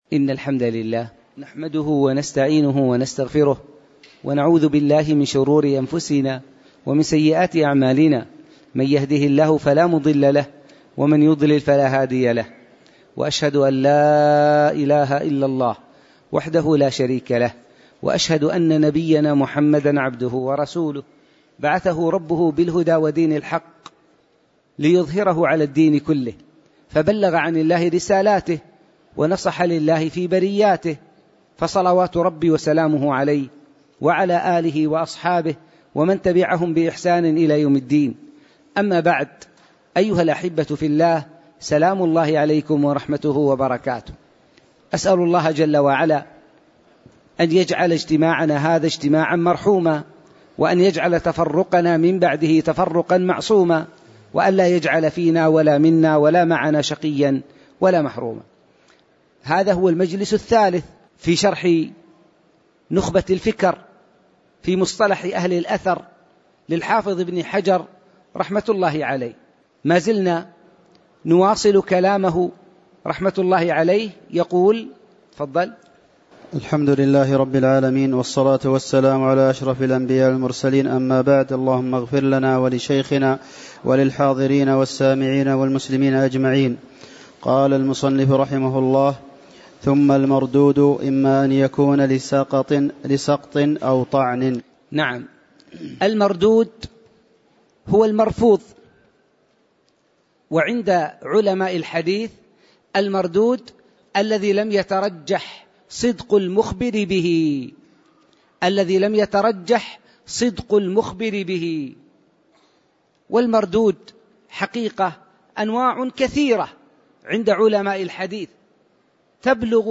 تاريخ النشر ١٣ شوال ١٤٣٩ هـ المكان: المسجد النبوي الشيخ